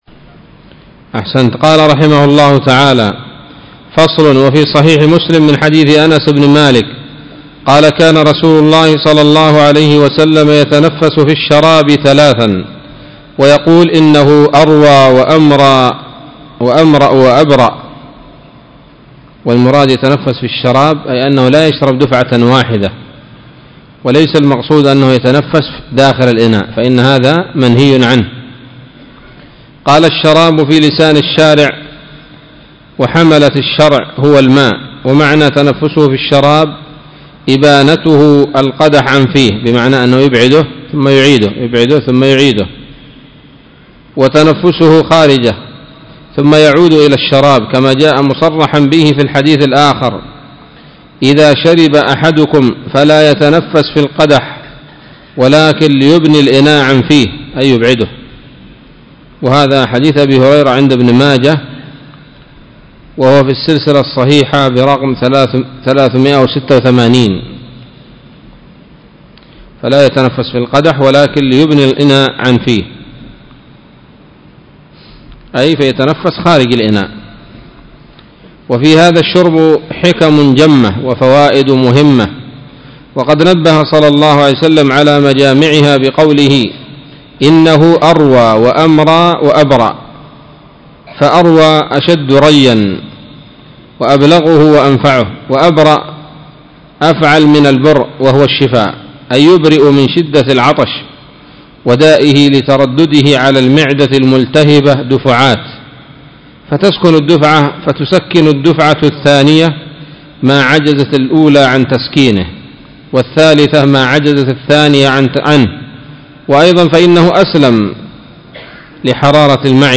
الدرس الثالث والستون من كتاب الطب النبوي لابن القيم